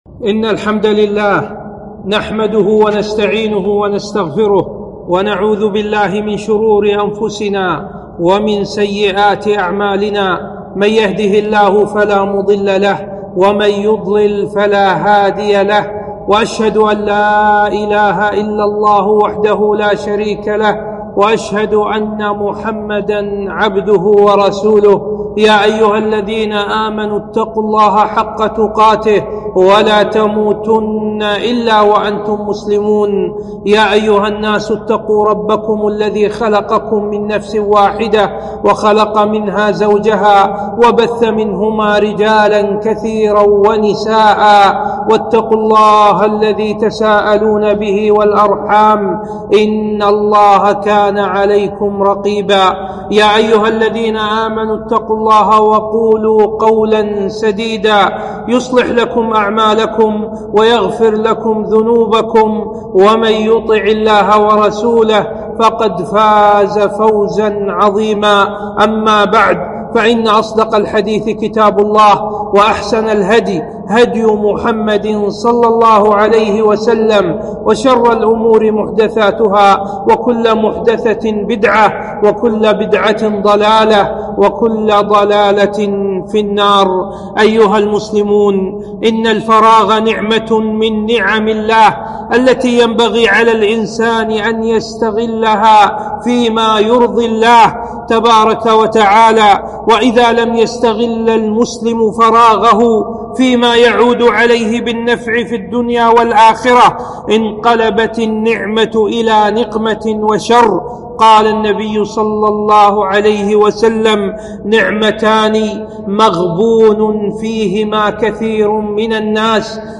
خطبة - استغلال الإجازة الصيفية